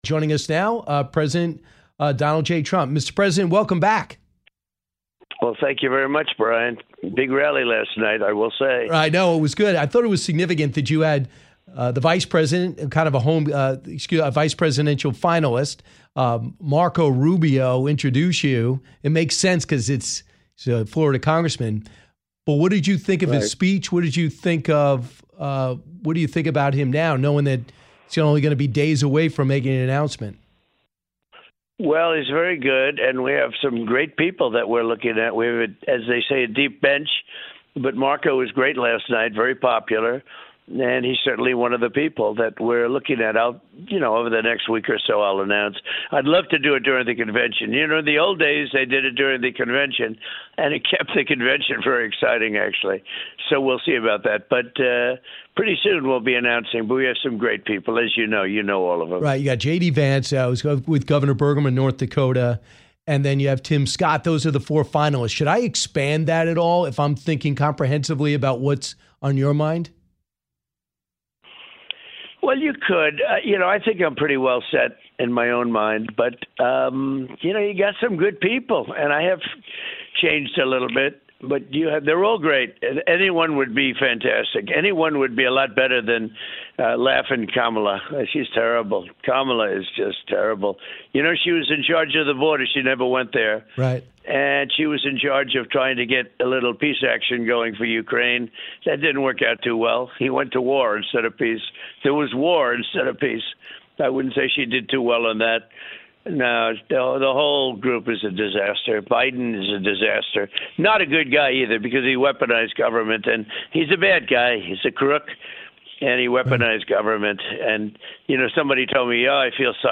Former President Donald Trump joined Brian this morning for a wide ranging interview on everything from President Biden's disastrous debate performance to why Nikki Haley was not invited to the RNC.